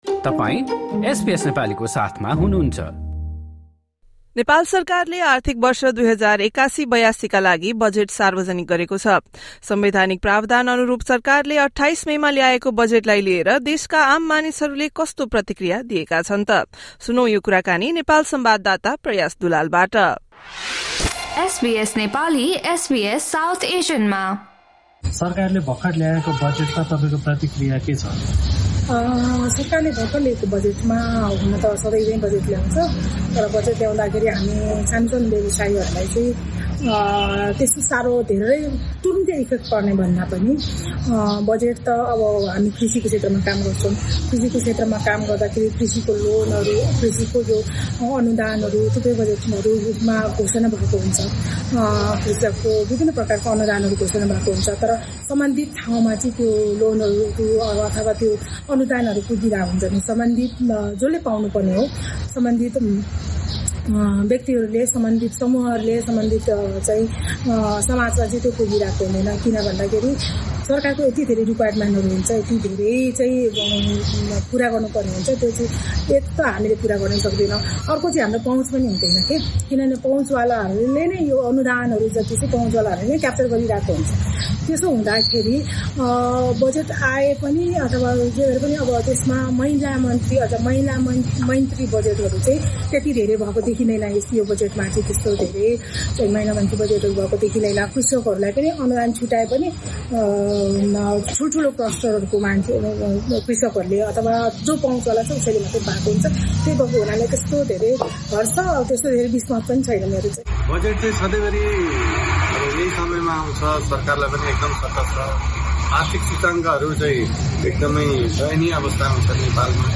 Community members in Nepal share their opinions about the country's latest budget announcement.